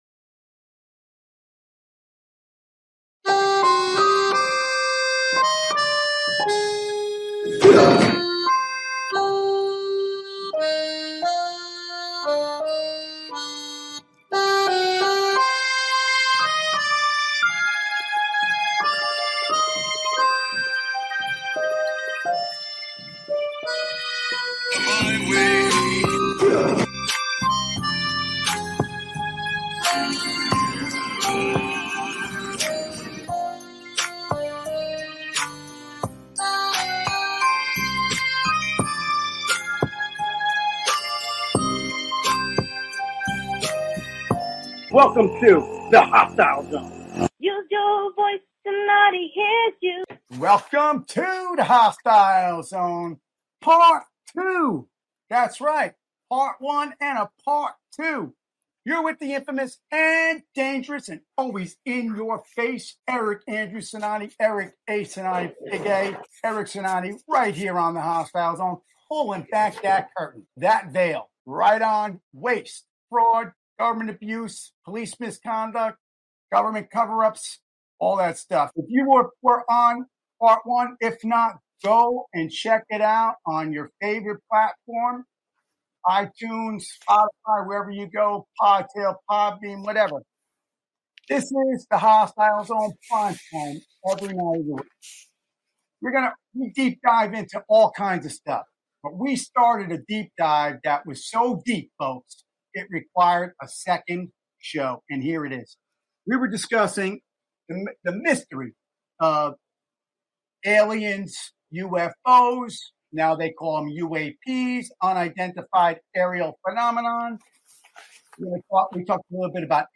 Talk Show Episode, Audio Podcast, Uncovering the UFO Mystery, Alien Technology, AATIP, or Military Secrets?